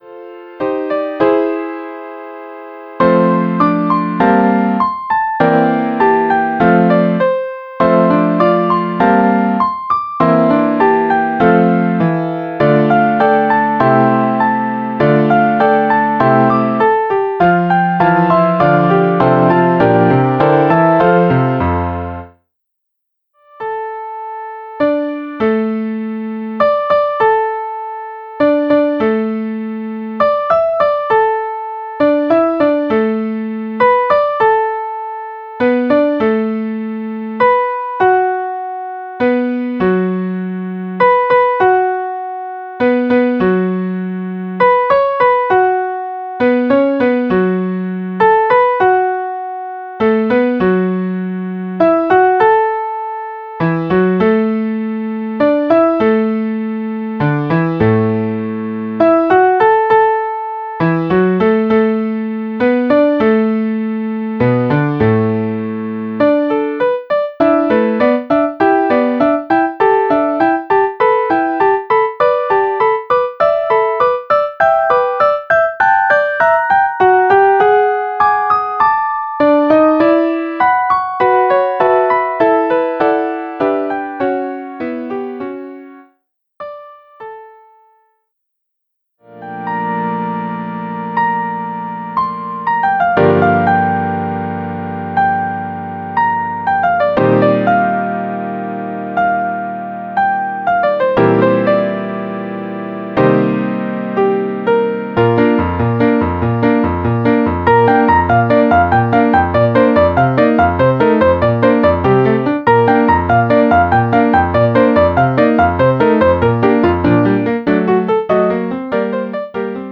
A rhythmic and engaging piece in a bright tempo.